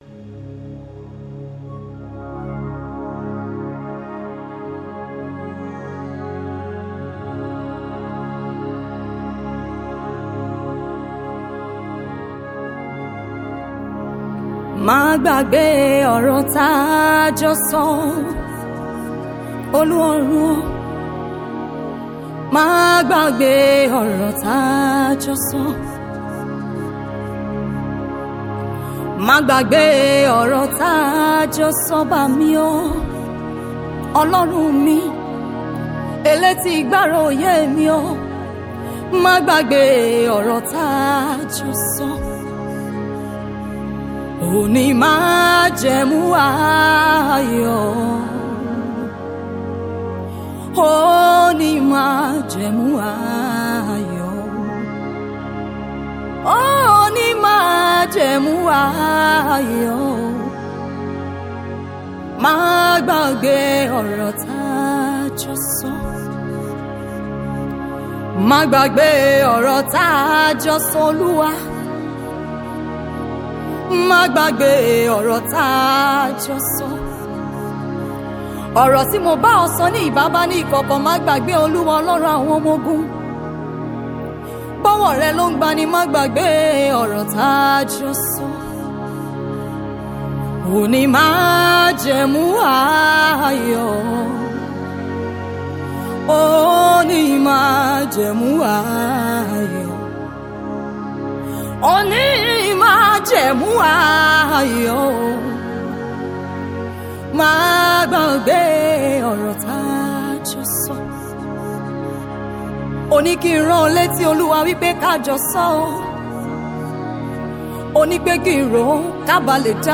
Yoruba Gospel Music
a powerful worship song to uplift and inspire you.